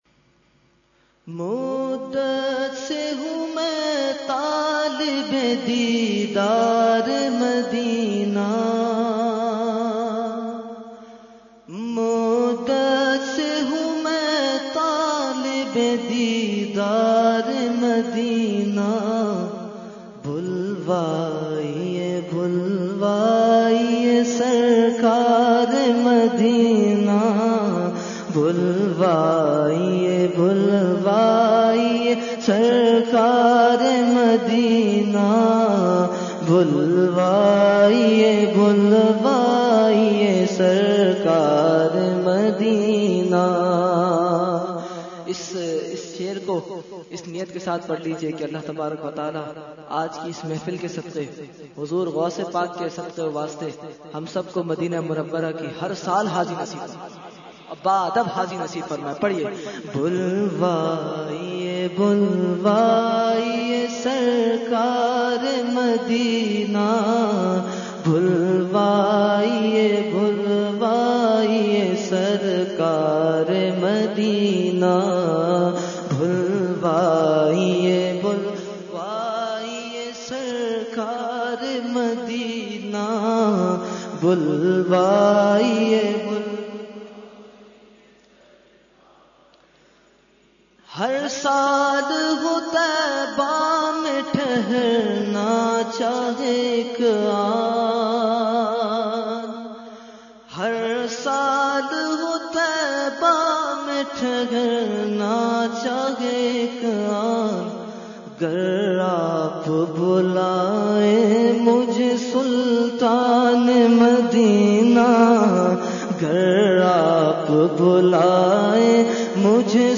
Category : Naat | Language : UrduEvent : 11veen Shareef 2018-2